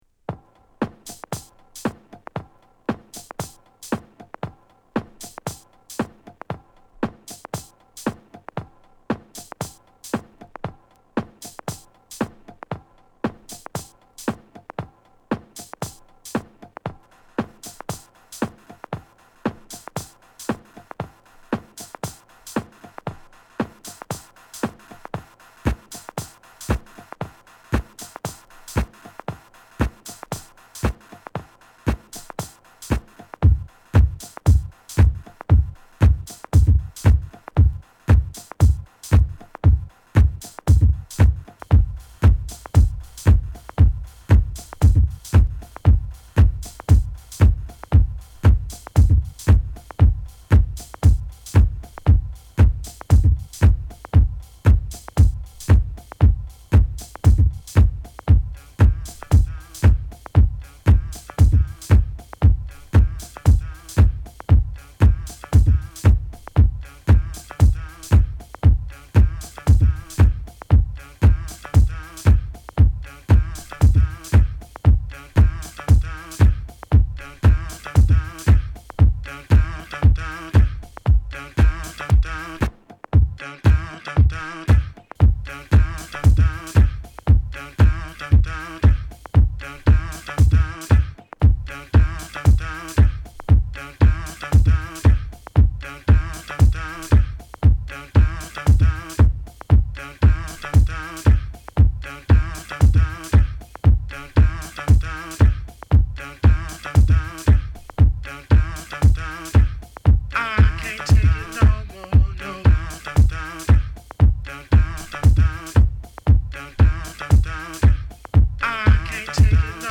重くスローに導かれる素晴らしい出来なのだが
アフロ・パーカッションにリズミカルなクラップ／ヴォイス・サンンプルが絡み、土着的な祭事を想わせるトライバル・トラック